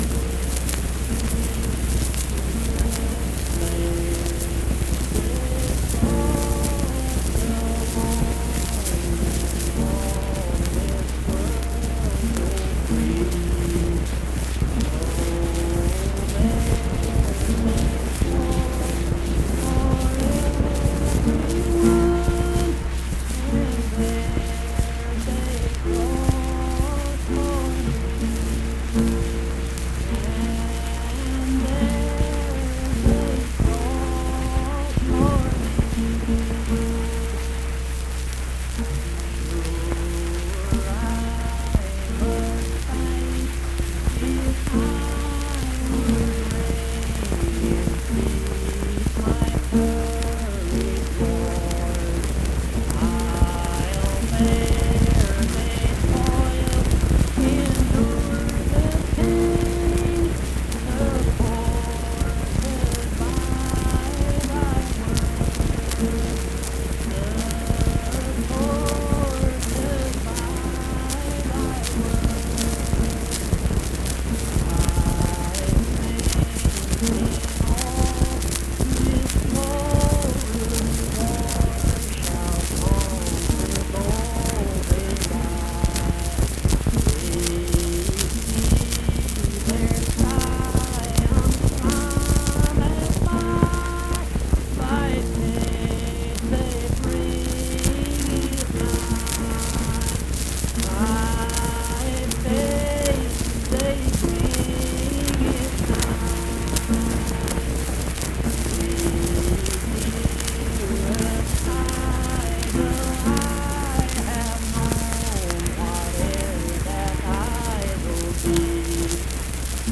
Accompanied (guitar) and unaccompanied vocal music
Performed in Mount Harmony, Marion County, WV.
Hymns and Spiritual Music
Voice (sung)